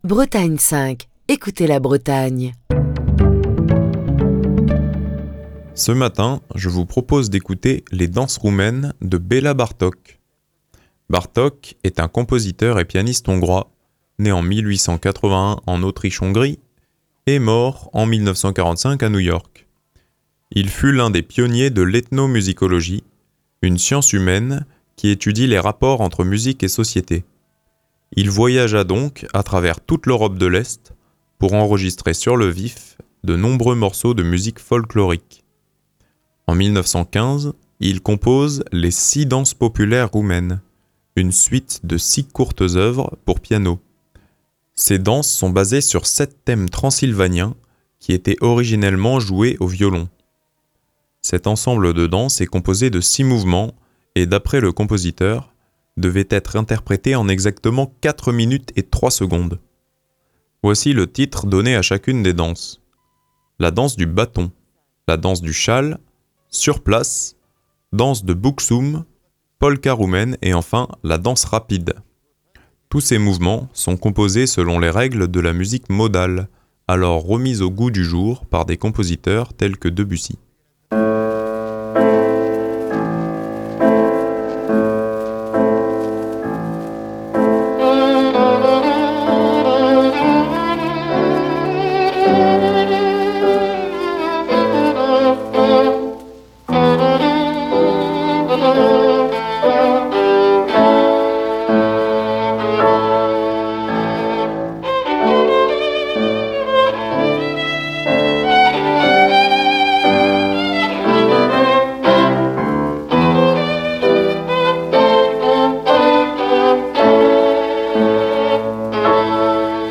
Ces danses sont basées sur sept thèmes transylvaniens, qui étaient originellement joués au violon. Cet ensemble de danses est composé de six mouvements et, d'après le compositeur, devrait être interprété en exactement quatre minutes et trois secondes. Voici le titre donné à chaque danse : • Danse du bâton) • (Danse du châle) • (Sur place) • (Danse de Bucsum) • (Polka roumaine) • (Danse rapide) Tous ces mouvements sont composés selon les règles de la musique modale, alors remise au goût du jour par des compositeurs comme Debussy.